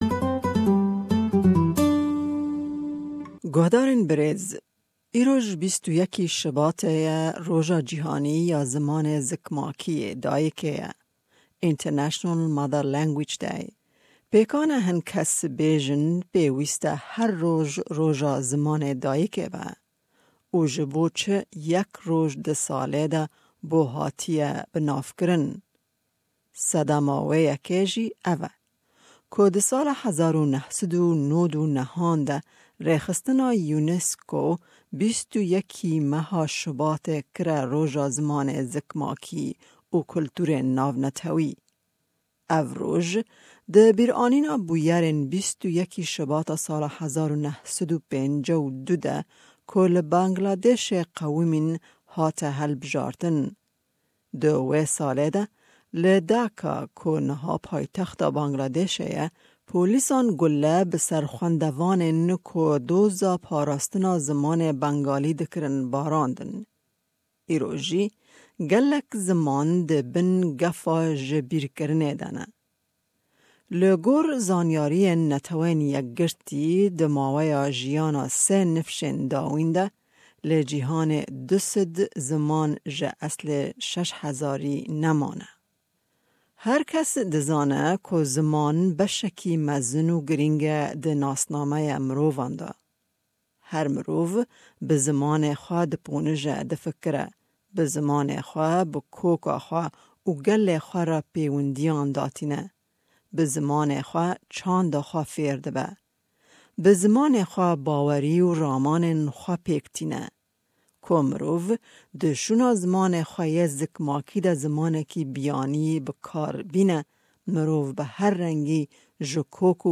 Hevpeyvîneke